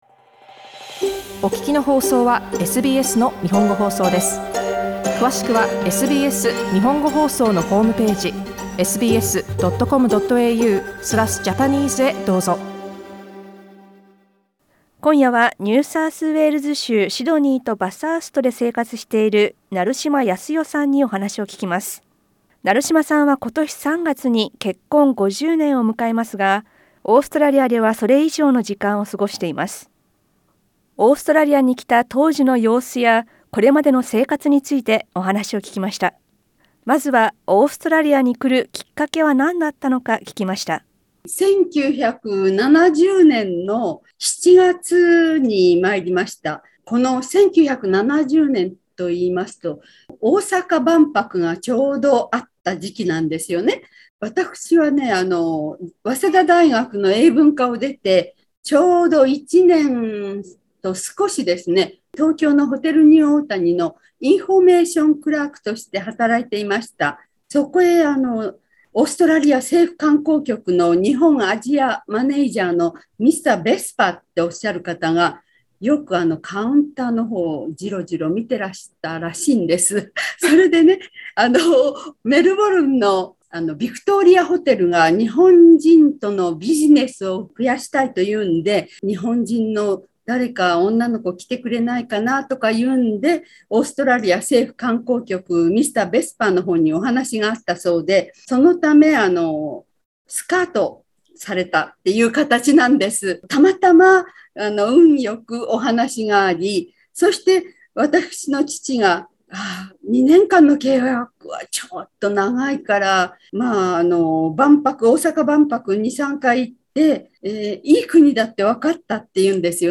音声インタビューは写真をクリックしてどうぞ。